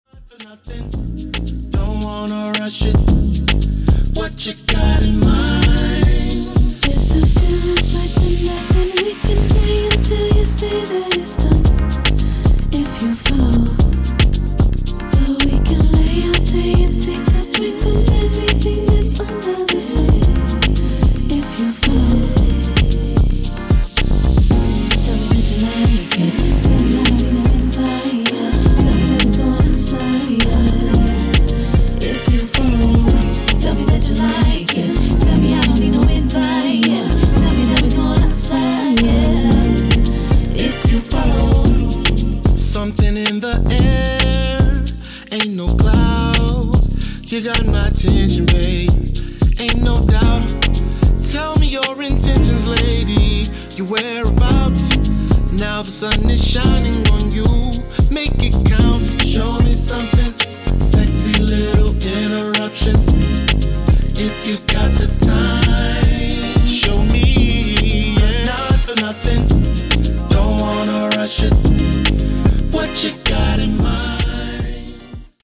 Hit-n-run blends and vinyl only amends.